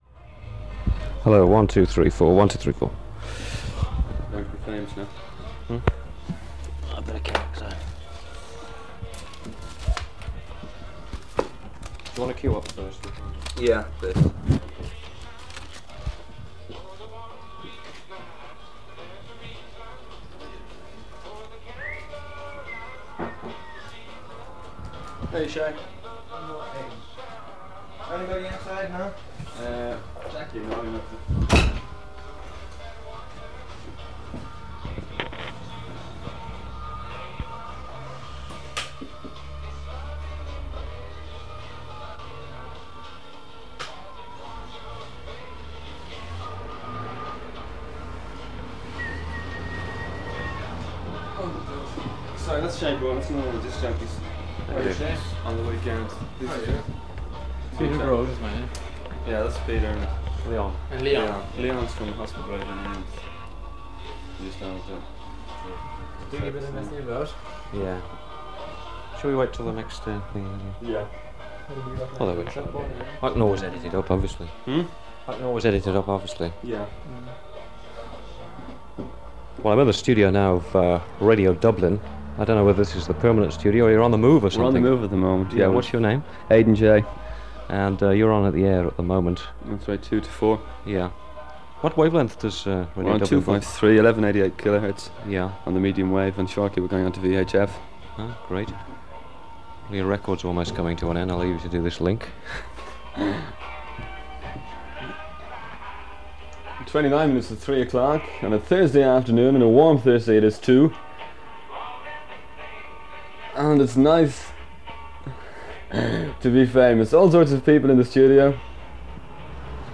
Interview with Aidan Cooney on Radio Dublin in 1979
This original recording from his collection features a visit to Radio Dublin and an interview with Aidan Jay (Aidan Cooney) about how the station was faring, its coverage area and plans to go on FM in the coming weeks.